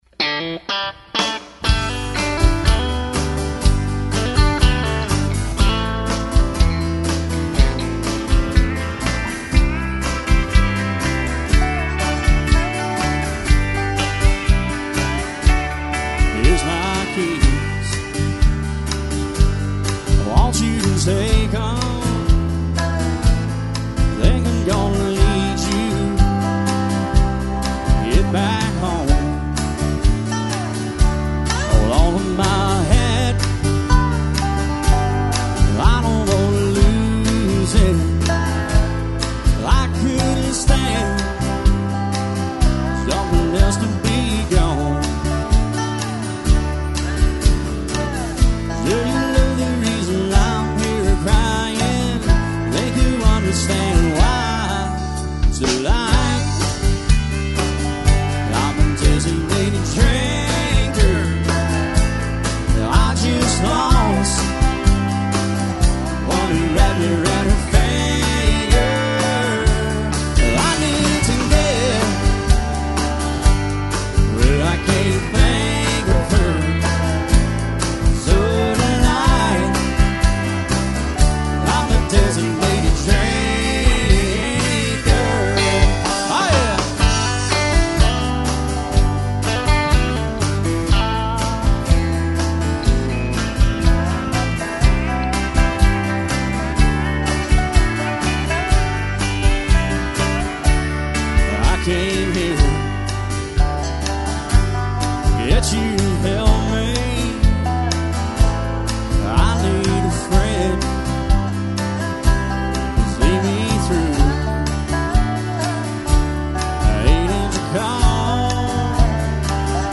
Live :: Location :: Mobile :: Recording
country